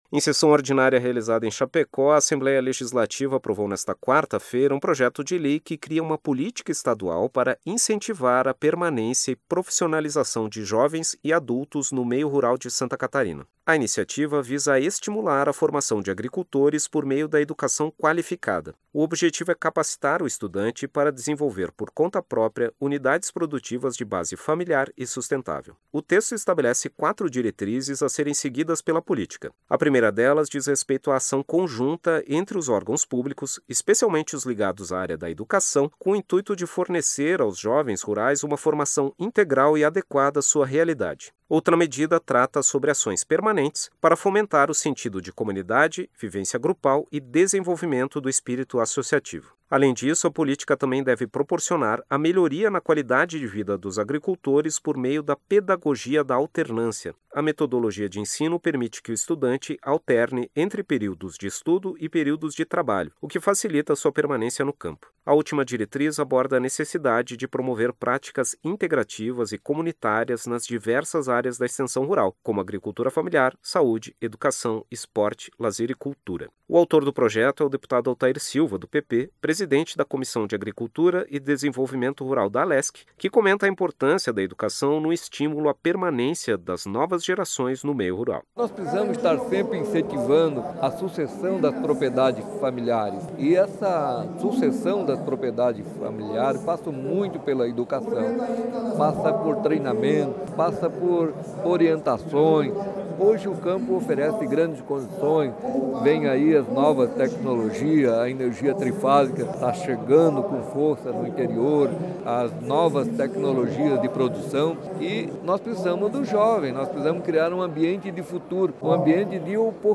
Entrevista com:
deputado Altair Silva (PP), autor do Projeto de Lei 20/2020.